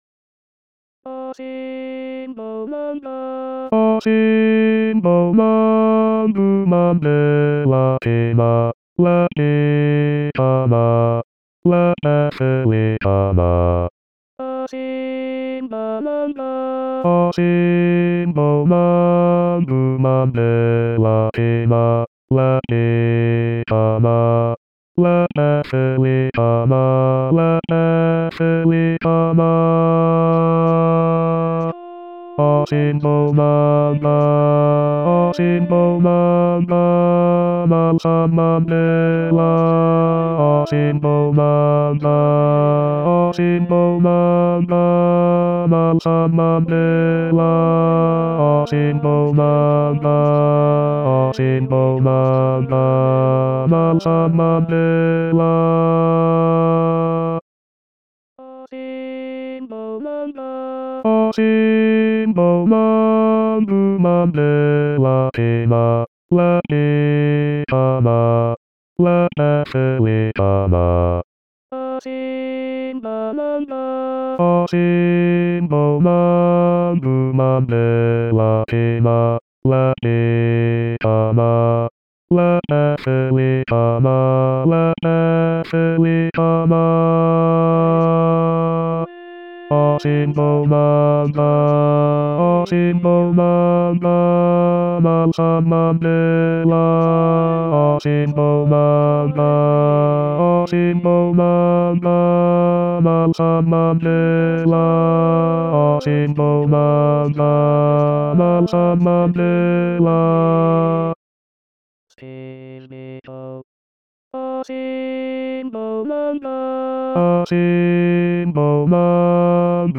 Asimbonanga barytons.mp3